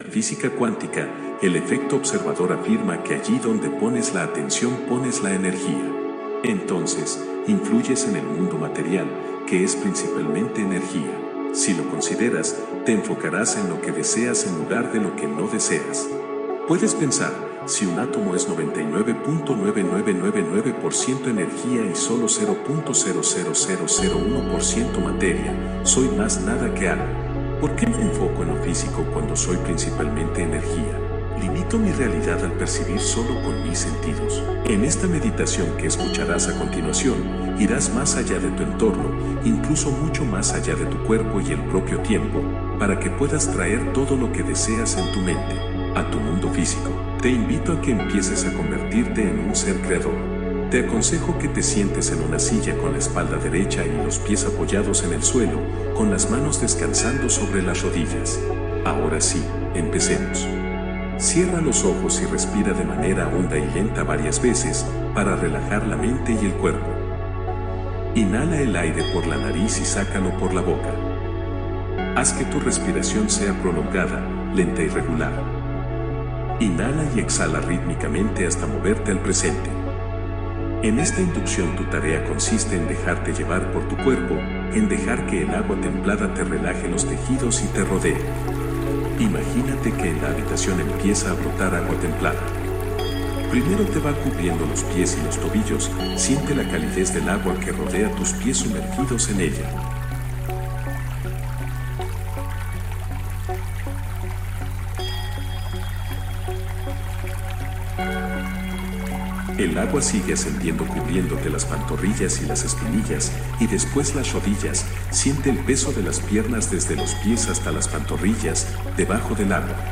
Meditación guiada para activar el campo cuántico interior